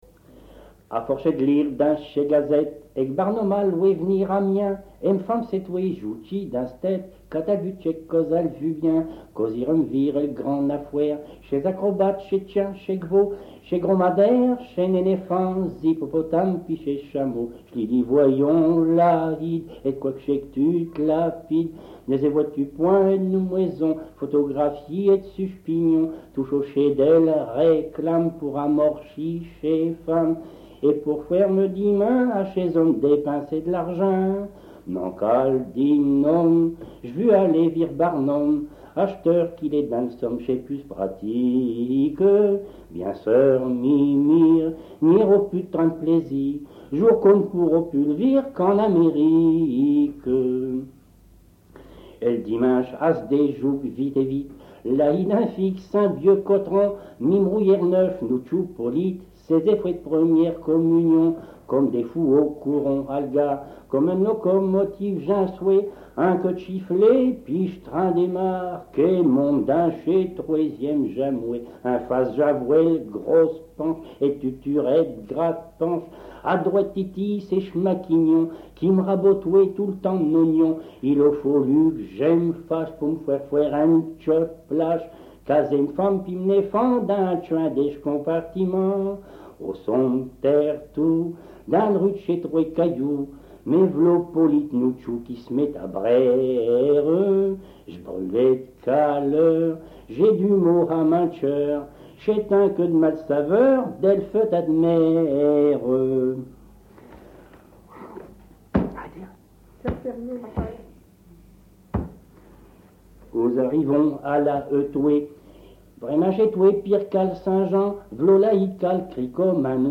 DIALECTE PICARD
Des Picards vous parlent Ch'mitan d'couq Filogone a battu sa femme Histoire d'un ancien maire Le cabri Le cirque barnum Le cornouilli